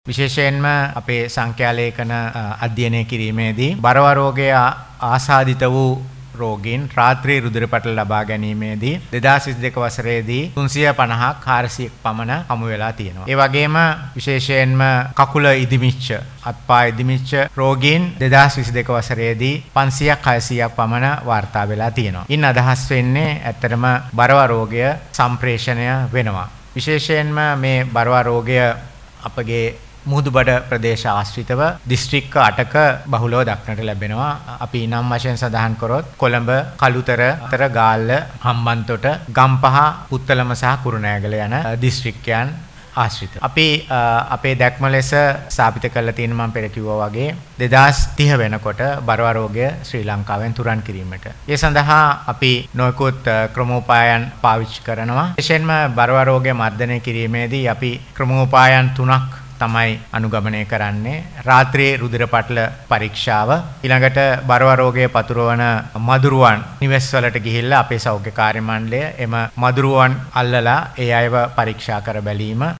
අද පැවති මාධ්‍ය හමුවකදී ඔහු මේ බව සදහන් කළා.